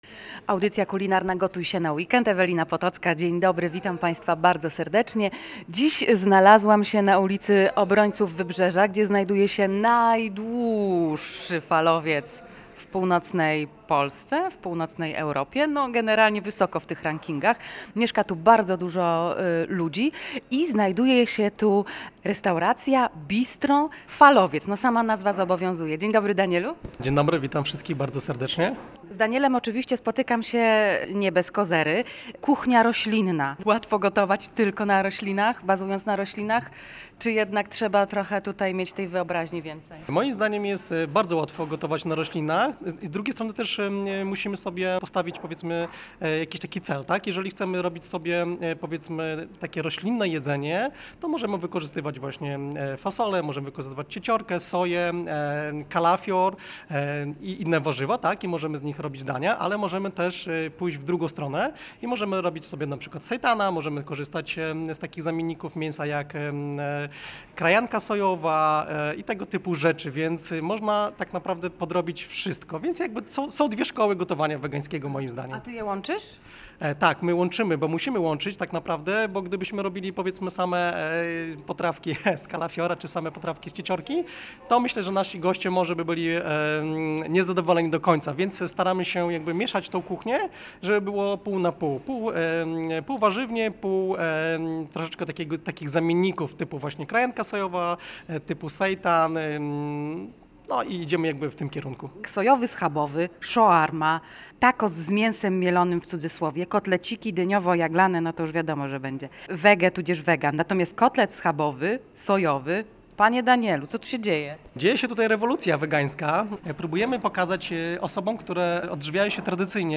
Rozmowa o nowalijkach z kucharzem propagującym kuchnię roślinną